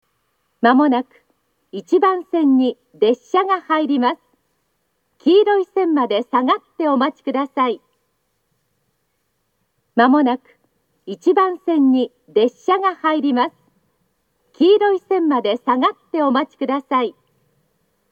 発車メロディーと接近放送があります。
１番線接近放送
aizu-wakamatsu-1bannsenn-sekkinn.mp3